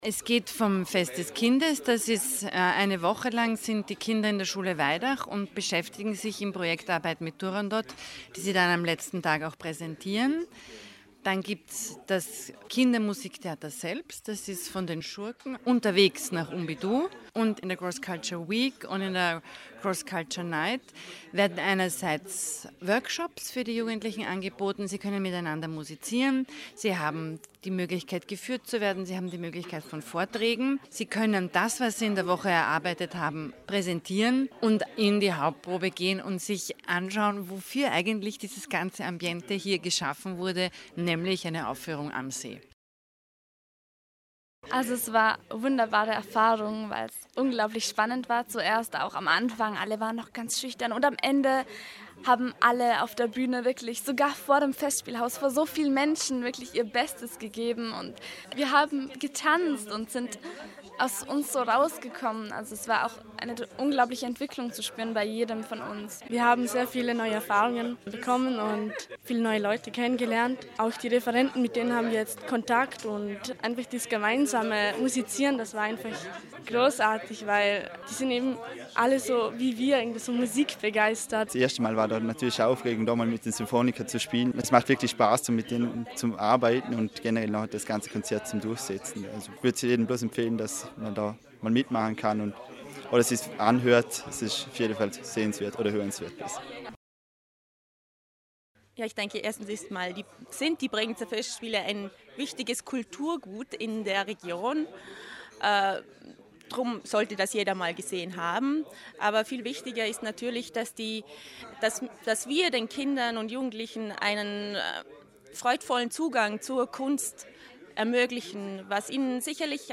O-Töne crossculture-Pressekonferenz - Beitrag
bregenz_crossculture-beitrag.mp3